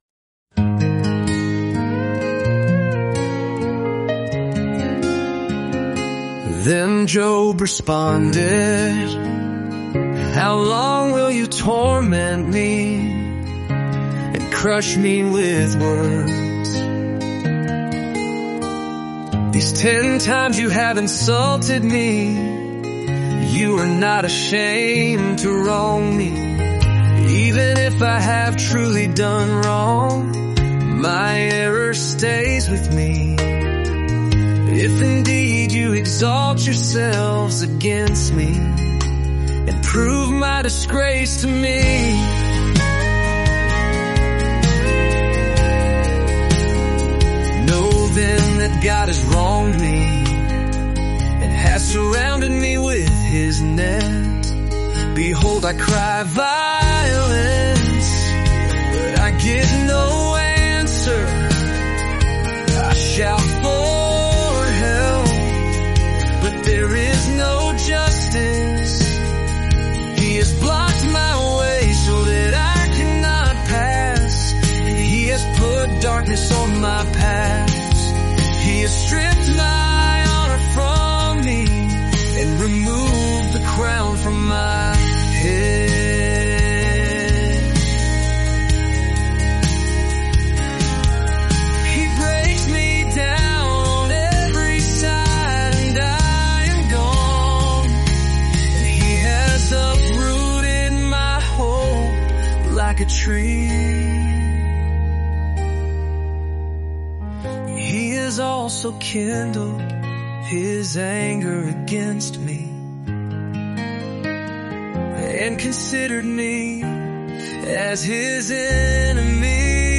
Walk with Job through his journey of deep suffering, honest questions, and steadfast faith in just 14 days through word-for-word Scripture songs.